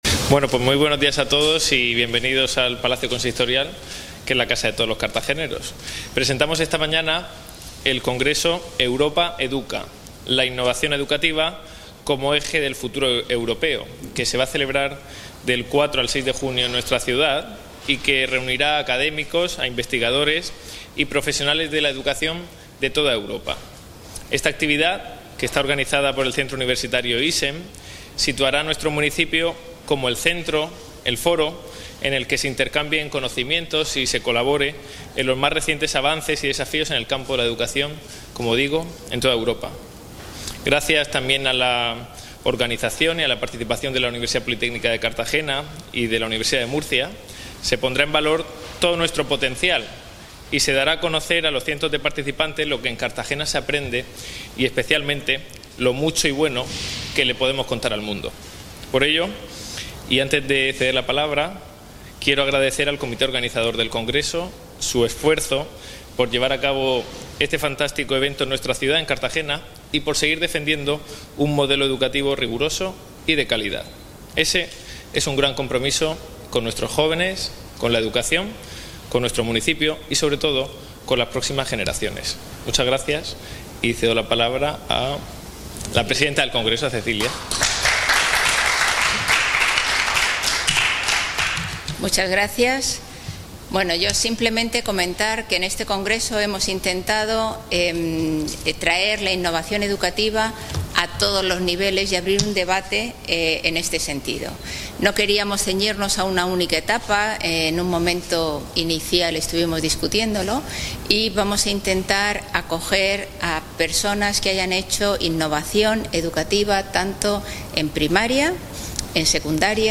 El concejal de Educación, Ignacio Jáudenes, ha informado a los medios de comunicación sobre este evento, en el que se abordan diferentes temas de interés.